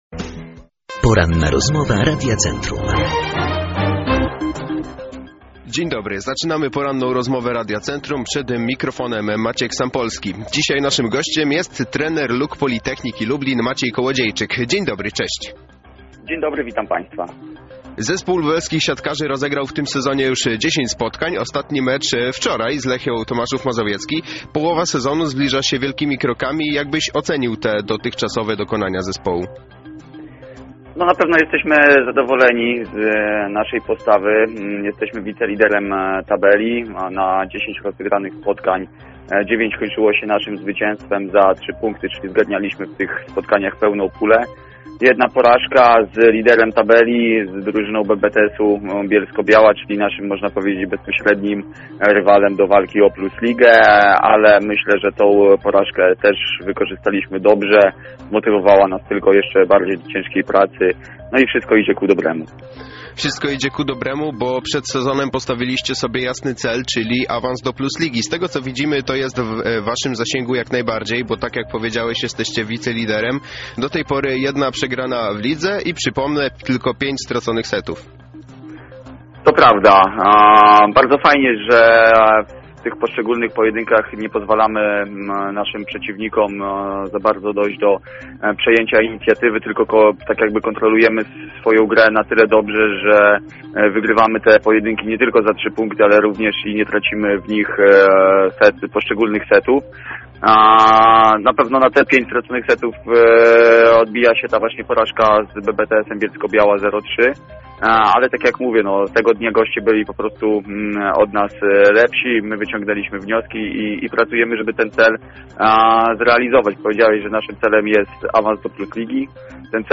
Cała rozmowa dostępna jest tutaj: